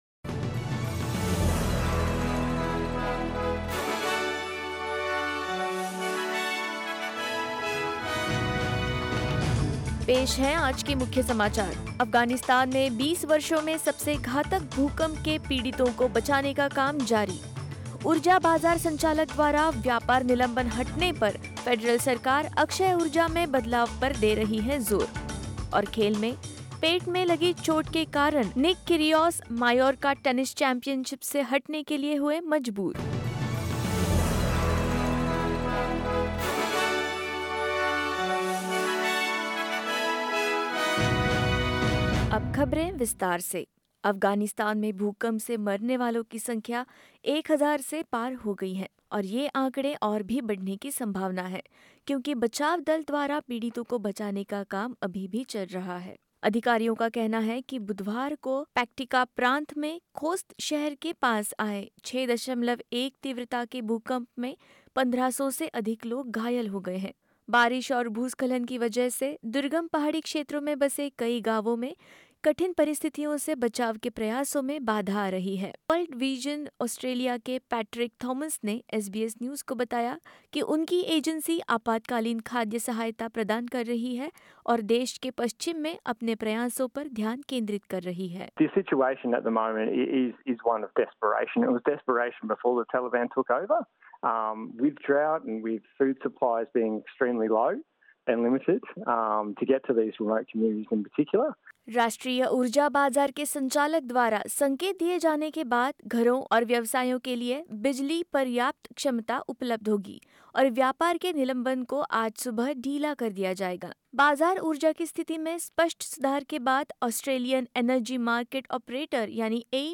In this latest SBS Hindi bulletin: Rescue teams work to save victims of Afghanistan's deadliest earthquake; Australian energy market operator has begun its staged approach to returning the market to normal; Nick Kyrgios forced to withdraw from the Mallorca Tennis Championships due to an abdominal injury and more